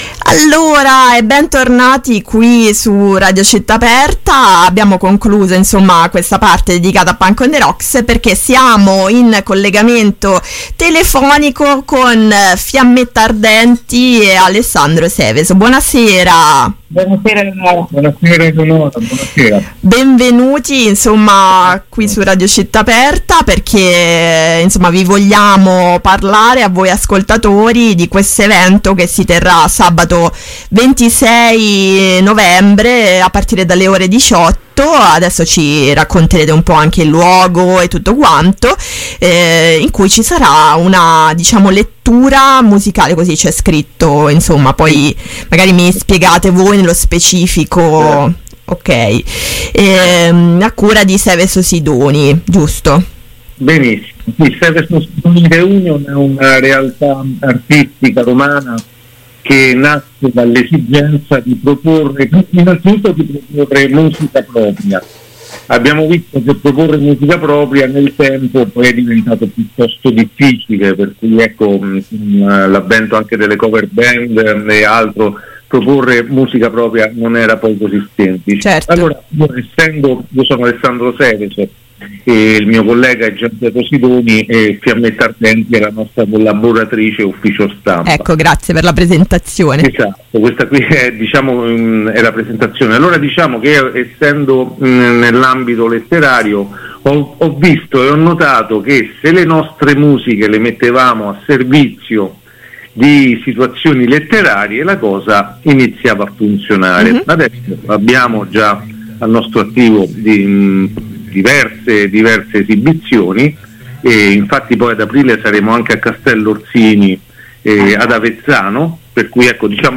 Intervista per “Ballata per Wislawa” | 21-11-22 | Radio Città Aperta